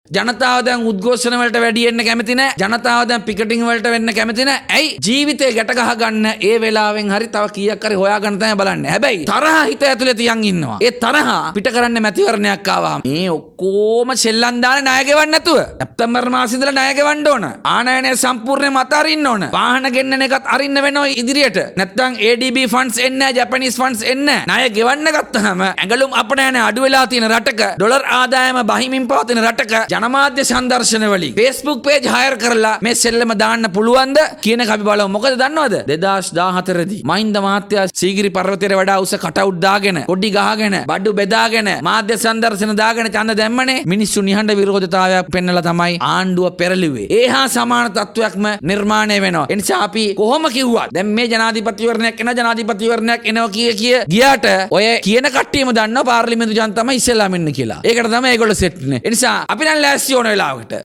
- පාර්ලිමේන්තු මන්ත්‍රී එස්.එම් මරික්කාර් මහතා
මන්ත්‍රීවරයා මේ බව පැවසුවේ ඊයේ කොළඹ පැවති මාධ්‍ය හමුවකට එක් වෙමින්.